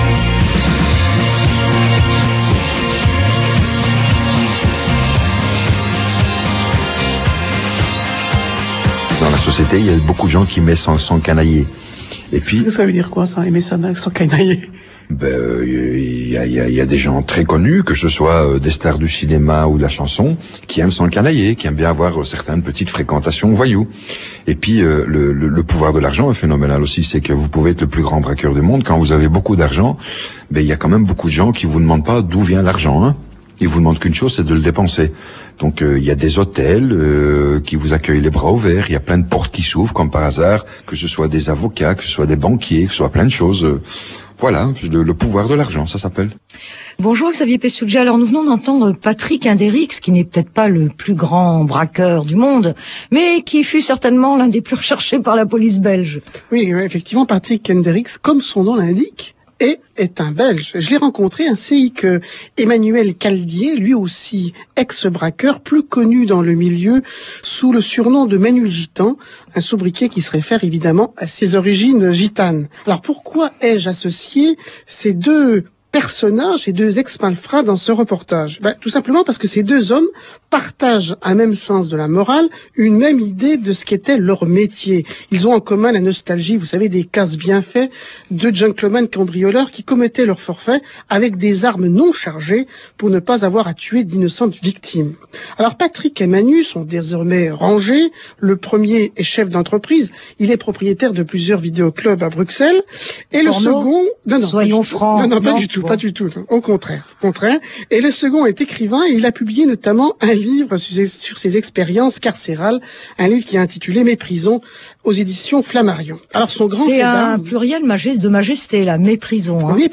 Entendu cette émission de Cosmopolitaine (souvent pleine de surprises !) dans ma voiture… et ne résiste pas à vous faire partager cet extrait.